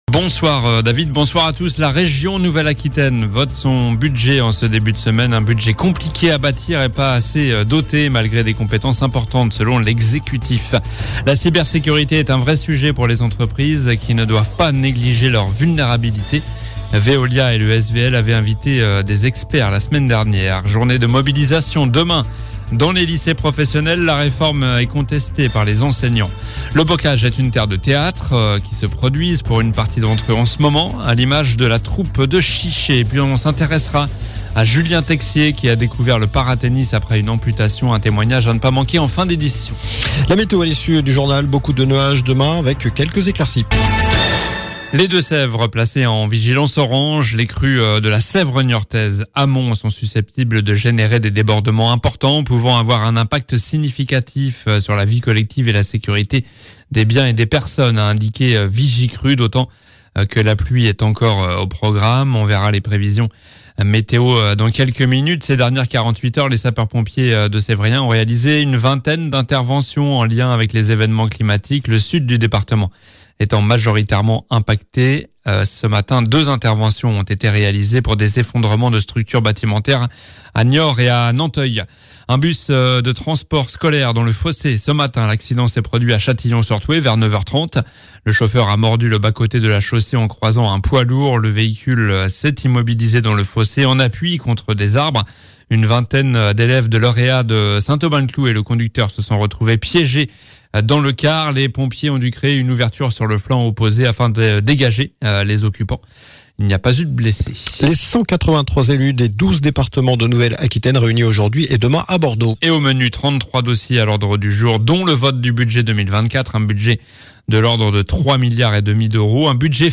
JOURNAL DU LUNDI 11 DECEMBRE ( soir )
infos locales